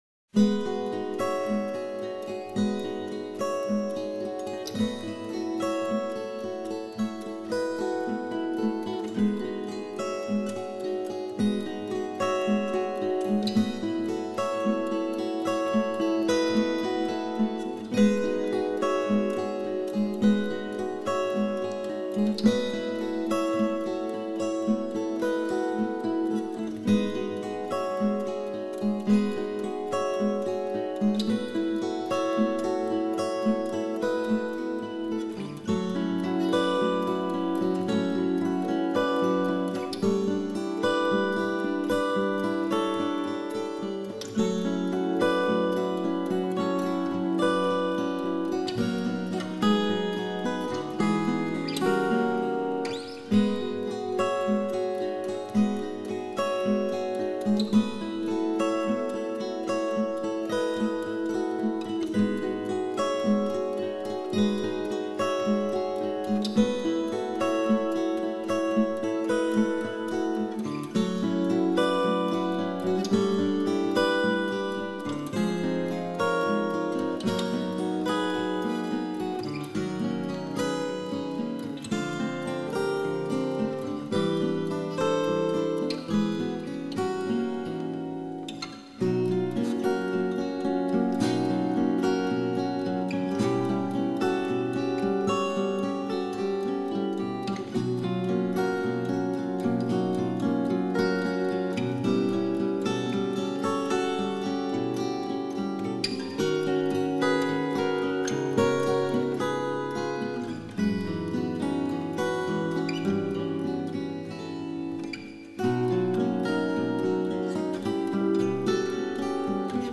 太好听了 声音清彻 亮丽
真美的音乐 声音清脆 有同感